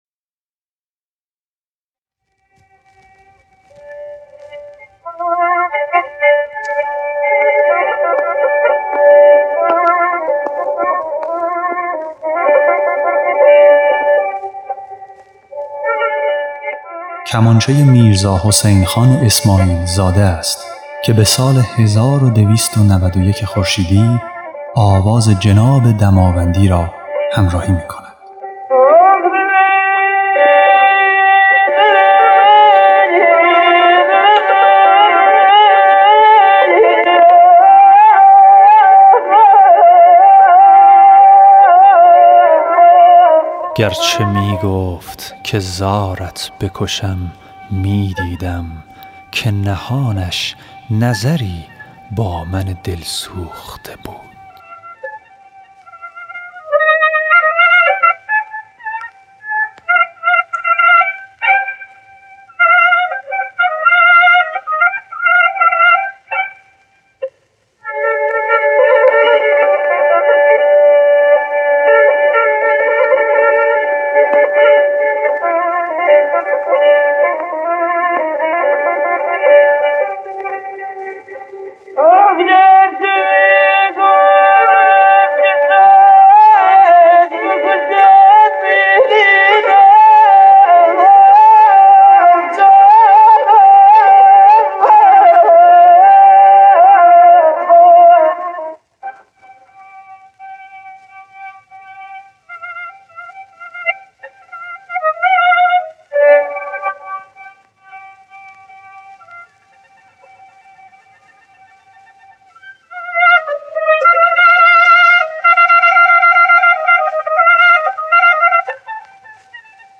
خواننده
نوازنده کمانچه